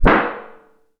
metal_tin_impacts_hit_hardt_03.wav